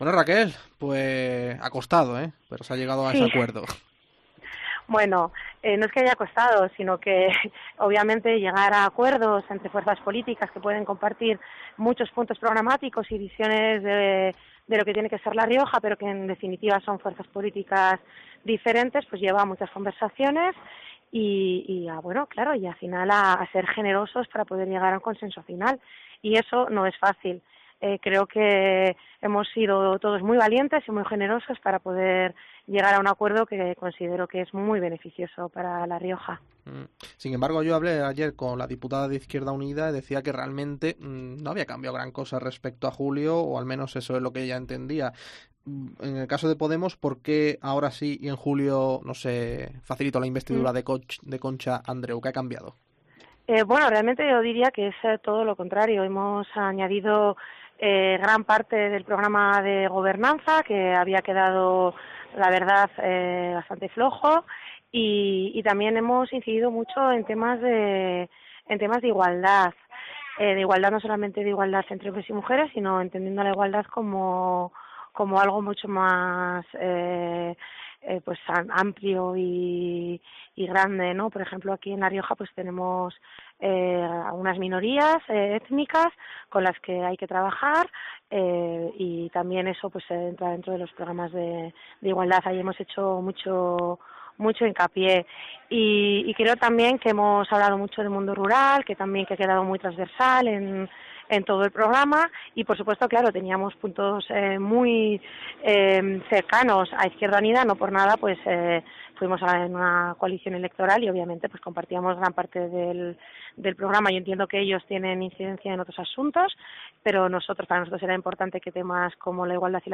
COPE ha entrevistado a la diputada morada en la comunidad riojana horas después del pacto de Gobierno con el PSOE